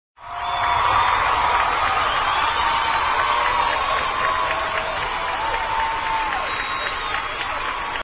Short Applause